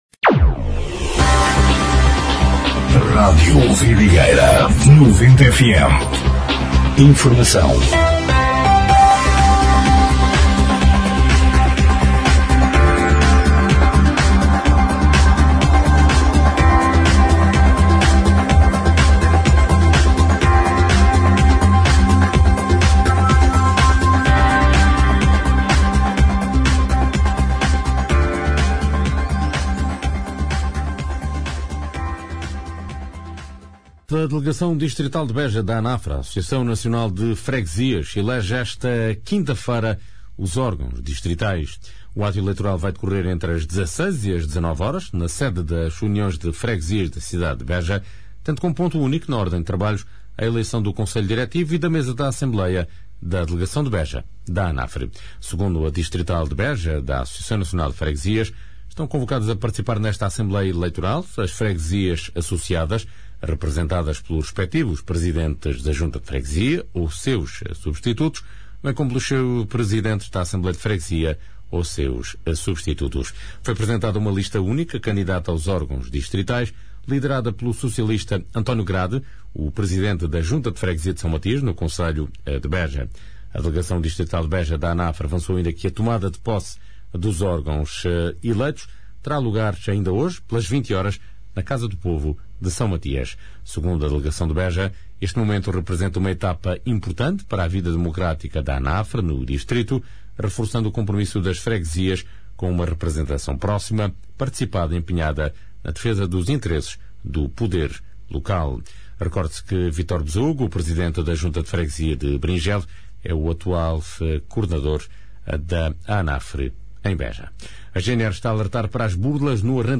Noticiário 16/04/2026